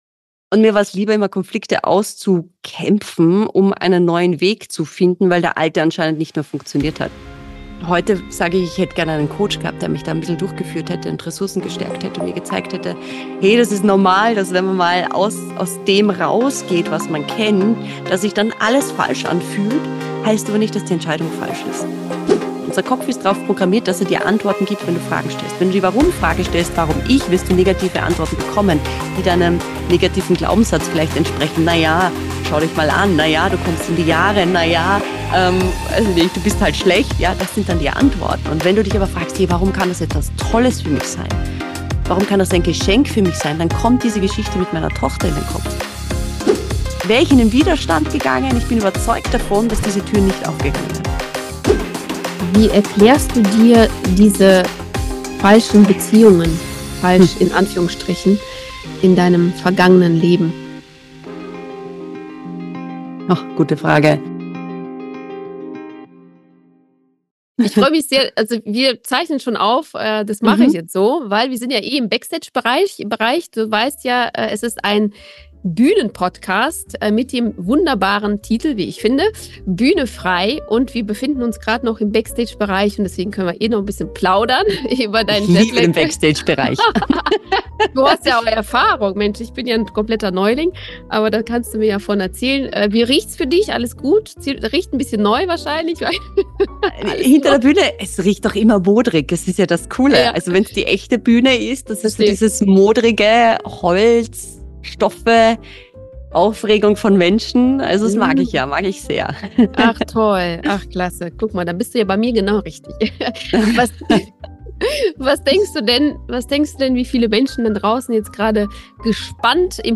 In der schwersten Zeit ihres Lebens, als sie nicht wusste, wie es weitergeht und wie sie es schafft, aus dem Tief wieder heraus zu kommen, trifft sie eine lebensmutige Entscheidung: die Perspektive zu wechseln. Freu dich auf ein sehr schönes tiefgründiges Gespräch mit einer TV- und Bühnenpersönlichkeit ganz ohne Starallüren